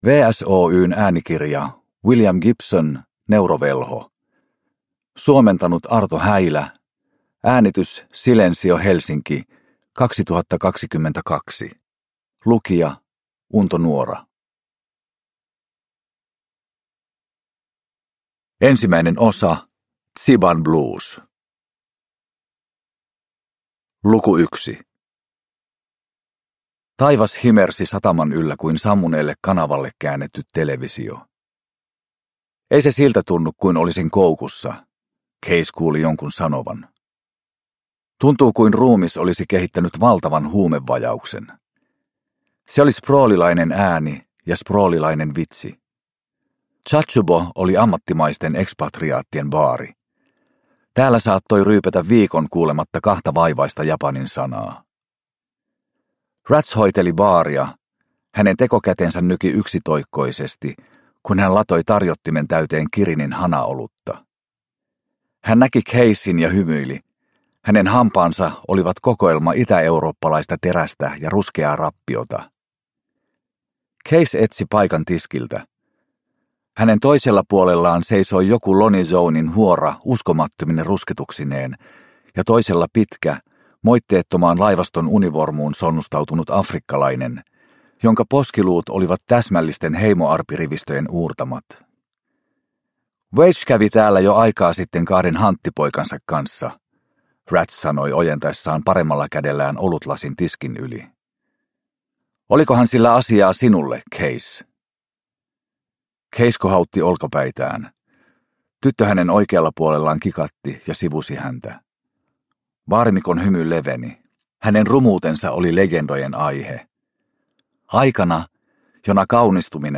Neurovelho – Ljudbok – Laddas ner